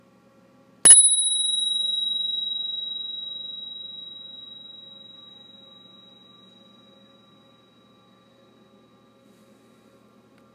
Spurcycle Bell
Then you have not yet heard its LOUD but dulcet tone.
Spurcycle’s superior bell will get the malefactors’ attention—and its lingering echo will afford them ample time to reflect on their perfidy as you blithely cycle past.
Click to Dinnggg:
Spurcycle Bell.m4a